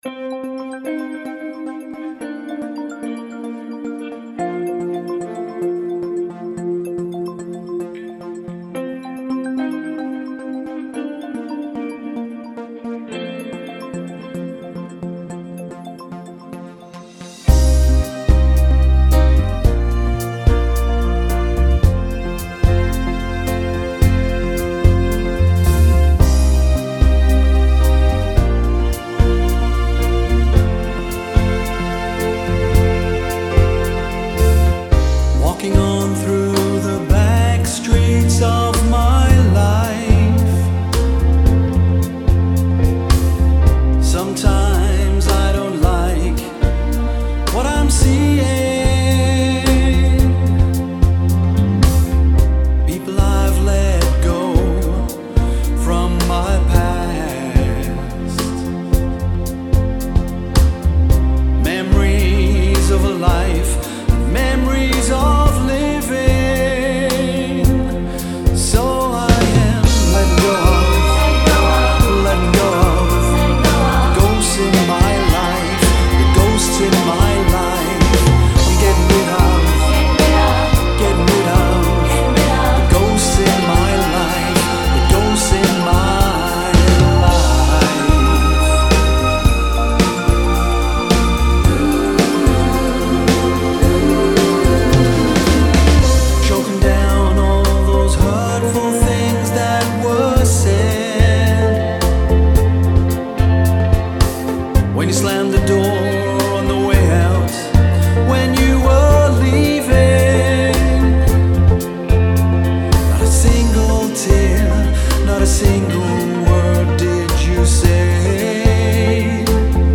Backing Vocals
Keyboards
Drums
Trumpet
Trombone
Tenor Sax
Additional Synths